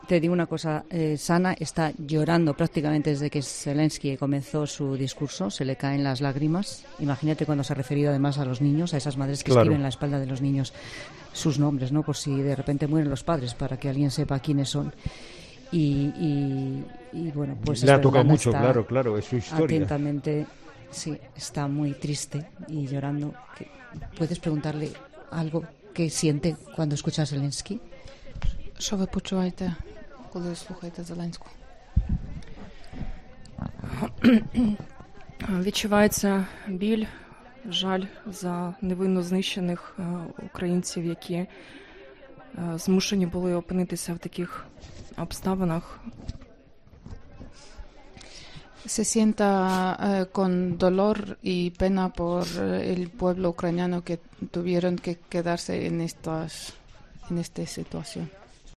Emoción y lágrimas de una familia ucraniana siguiendo el discurso de Zelenski en 'La Tarde' de COPE
Tristeza y lágrimas de la mujer que huyó hace dos semanas por la guerra al escuchar a Zelenski